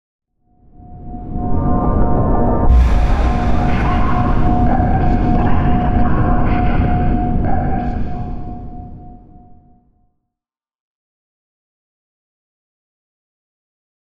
divination-magic-sign-circle-intro-fade.ogg